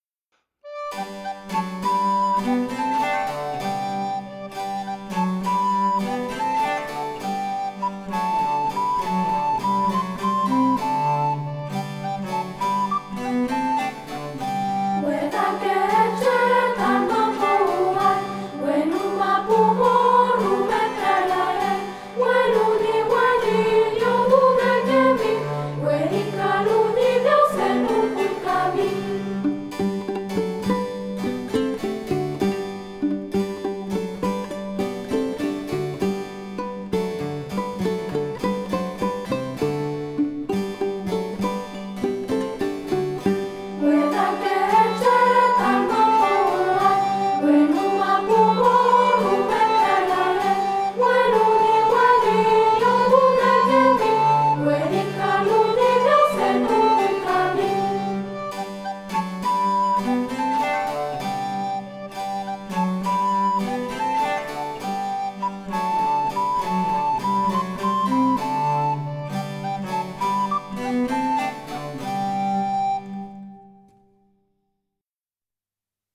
Música religiosa
Música vocal